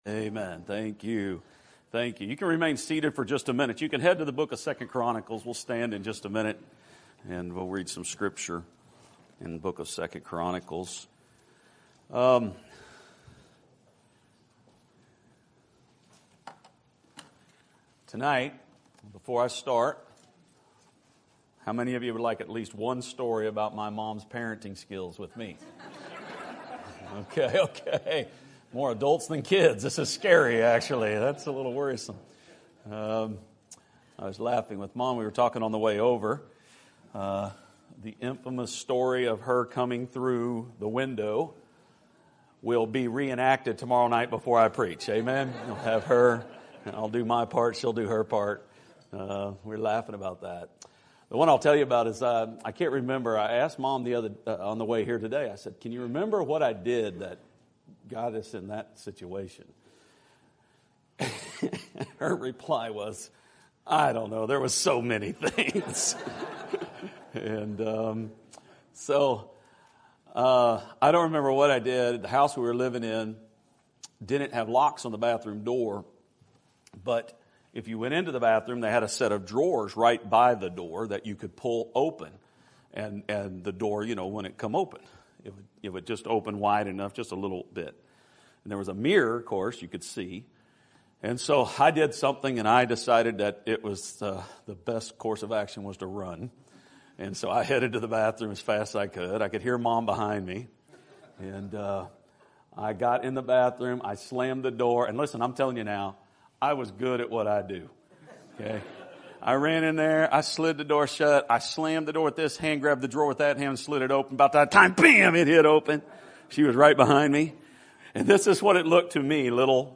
Sermon Topic: Spring Revival Sermon Type: Special Sermon Audio: Sermon download: Download (15.68 MB) Sermon Tags: 2 Chronicles Seek Humble Repent